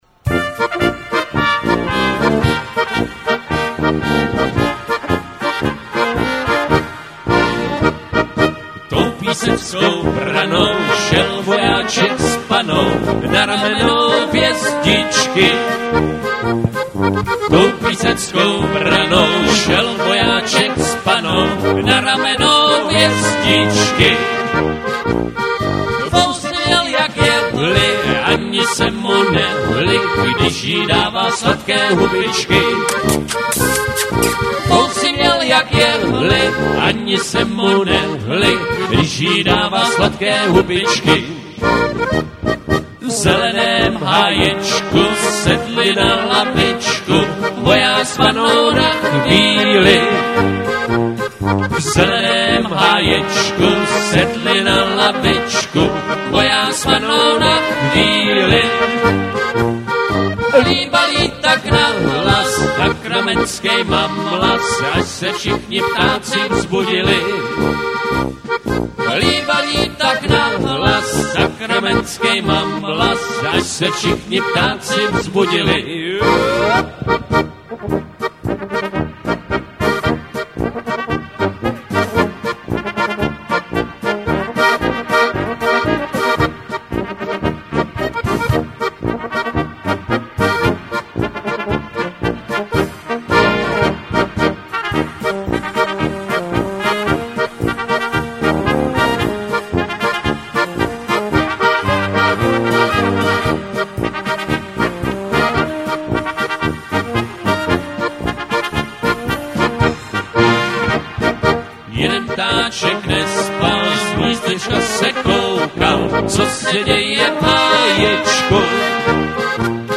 Klasické polky: